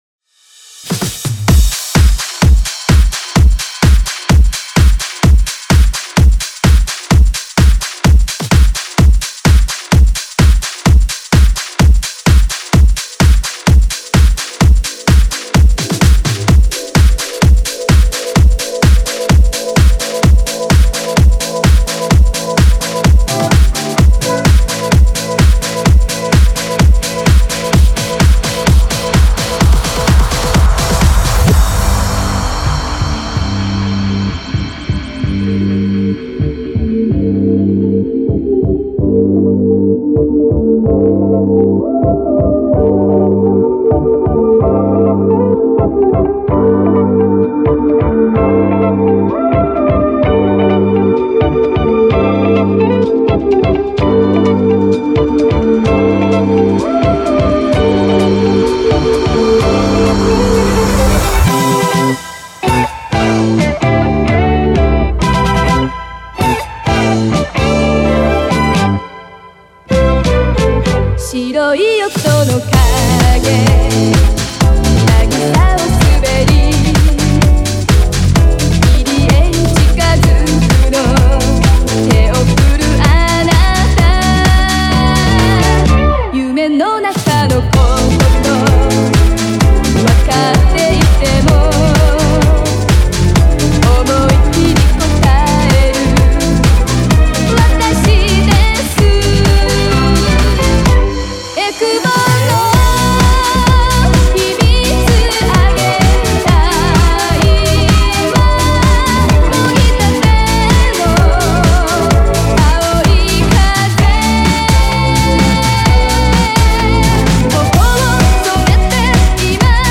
Genre(s): Edit/House